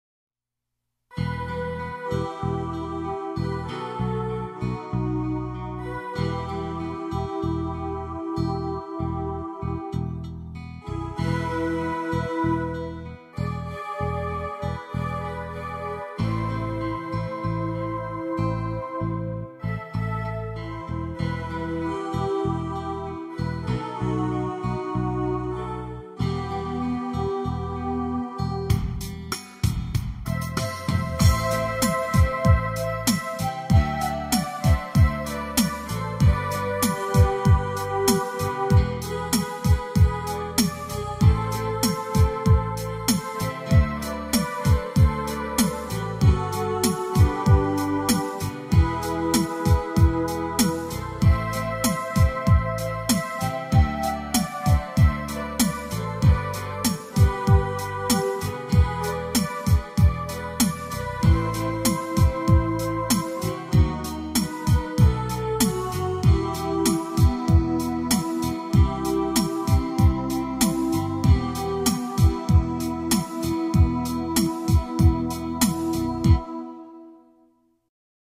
118-Grupero-Balad-3.mp3